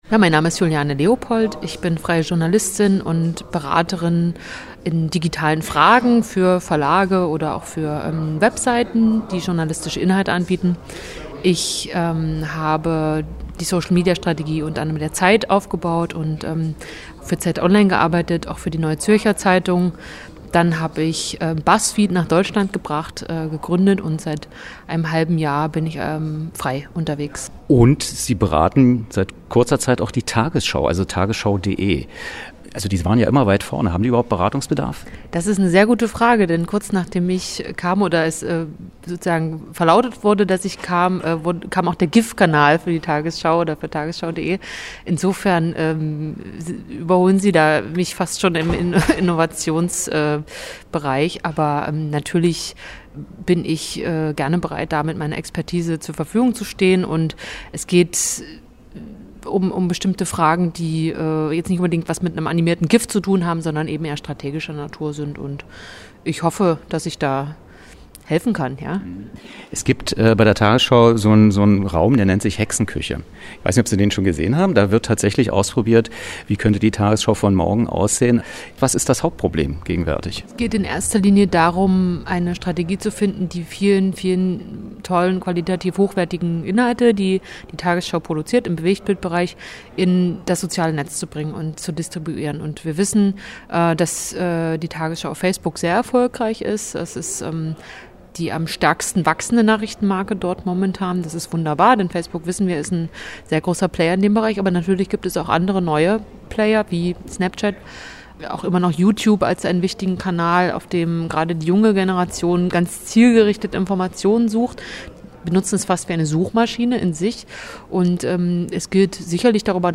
Interview über facebook, Google und die Rolle der konventionellen Medien